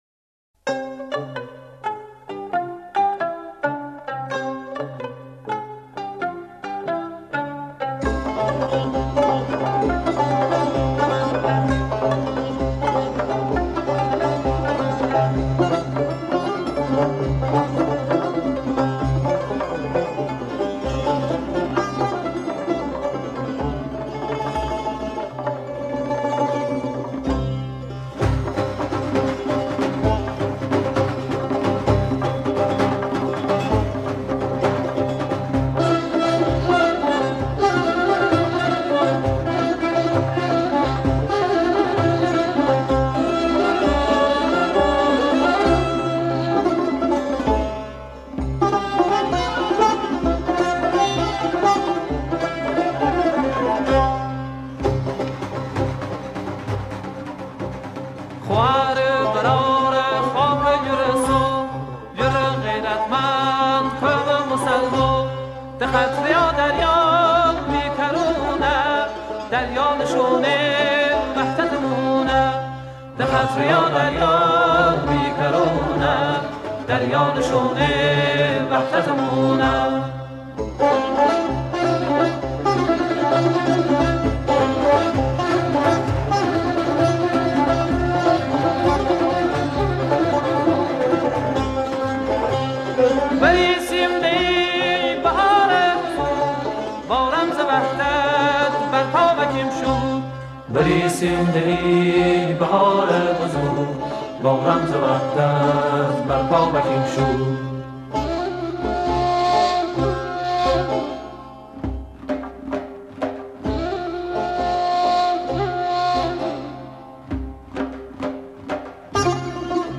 شعر این سرود به زبان لری و با مضمون انتخابات ساخته شده.